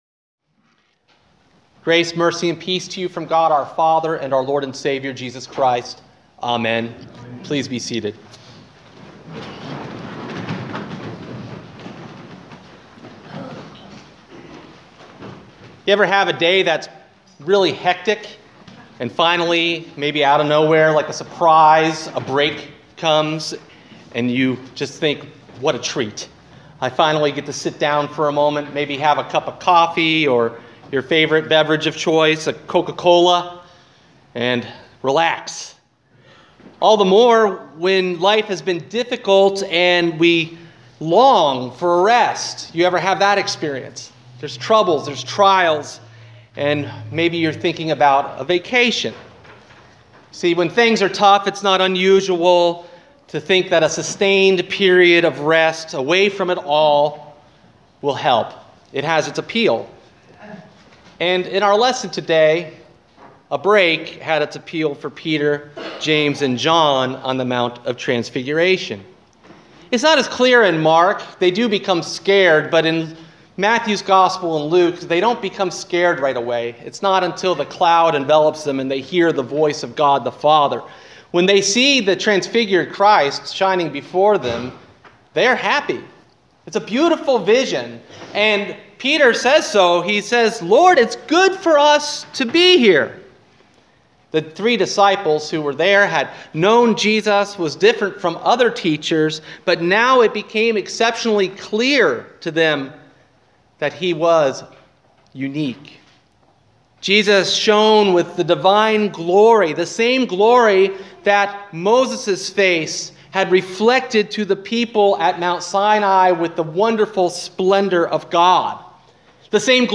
Sermon: Transfiguration – Mark 9:2-8